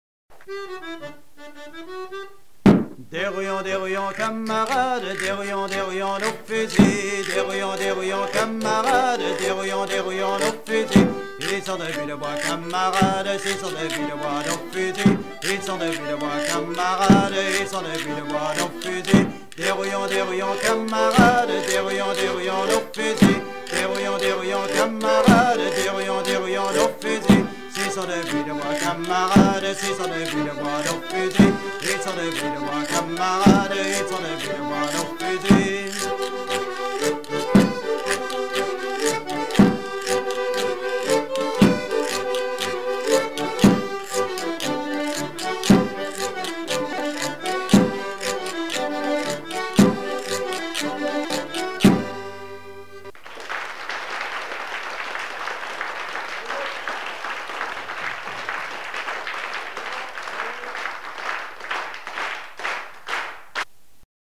danse : branle : courante, maraîchine
Pièce musicale éditée